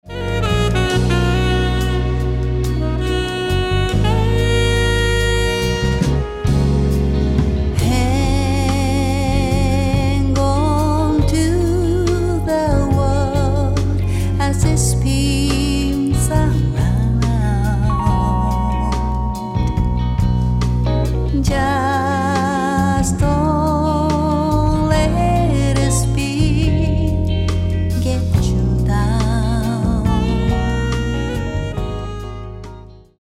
円熟のヴォーカルが描く、ソウル・ジャズ。
Vocal/Chorus
Bass/Percussion
Drums
Sax/Percussion
Guitar
Keyboard